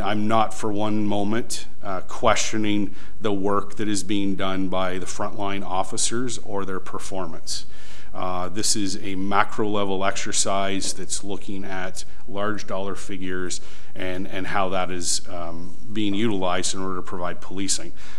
At Monday’s meeting, councillors voted against a motion asking for such a review to be considered in the 2025 operating budget discussions.